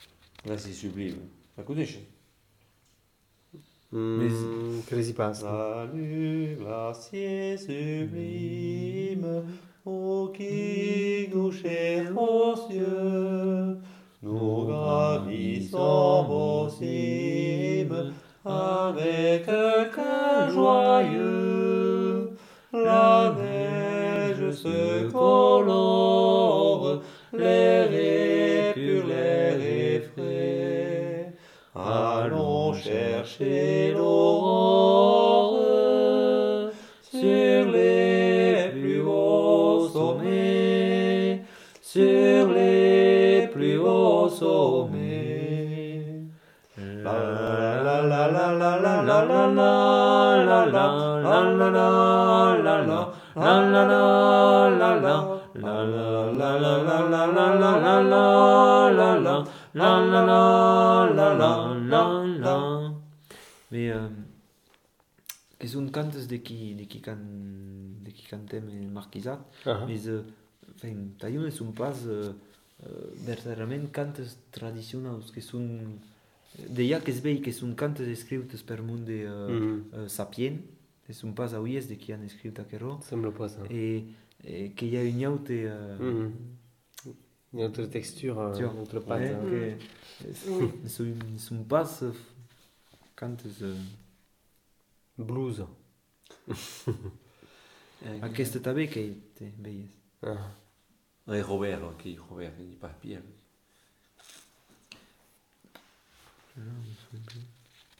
Lieu : Bénac
Genre : chant
Effectif : 2
Type de voix : voix d'homme
Production du son : chanté ; fredonné
Descripteurs : polyphonie
Classification : chanson identitaire